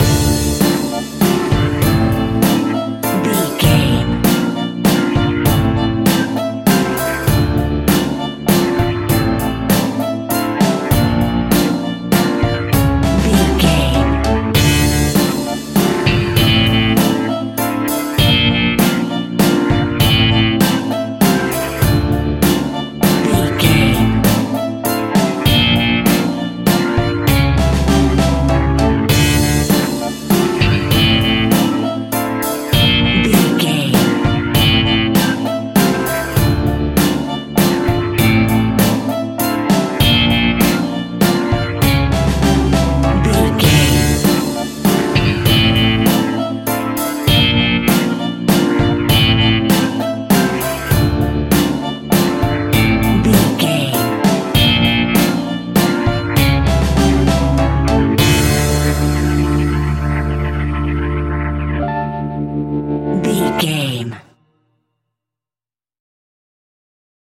Aeolian/Minor
ominous
haunting
eerie
electric guitar
violin
piano
strings
bass guitar
drums
percussion
horror music